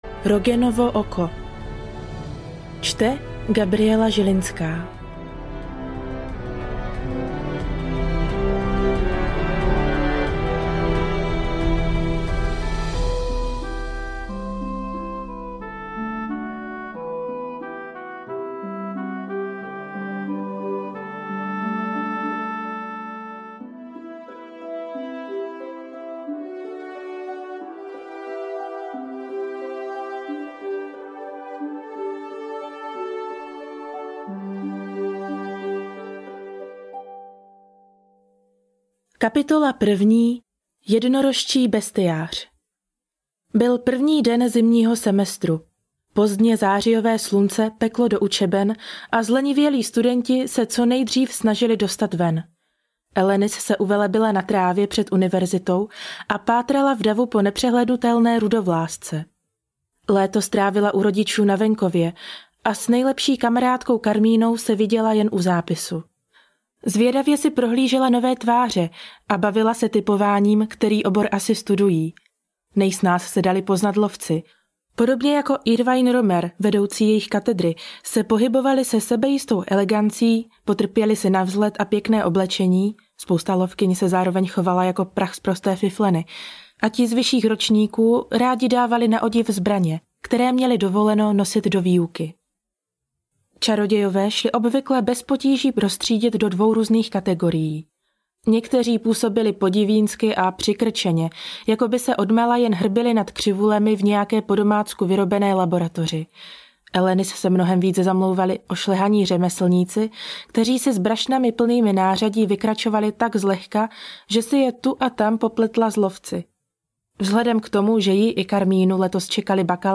Stíny nad univerzitou v Tir Erath – Rogenovo oko audiokniha
Ukázka z knihy